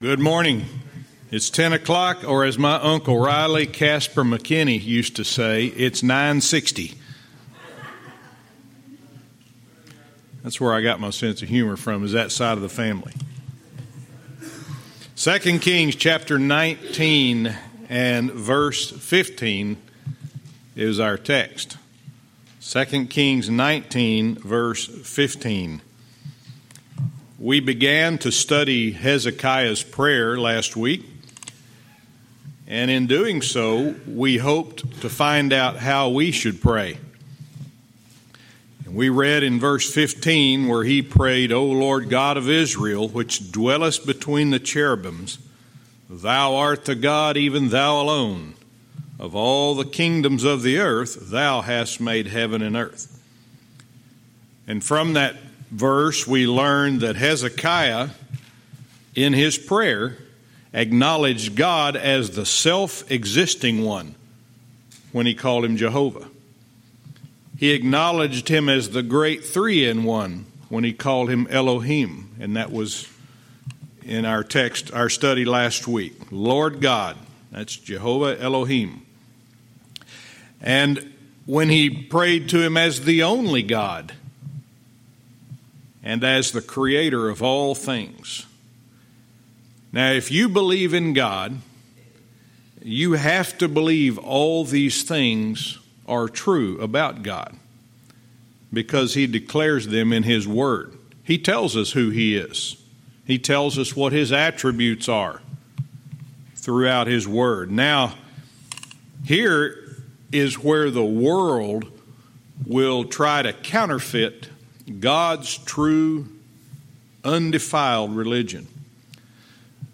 Verse by verse teaching - 2 Kings 19:15-18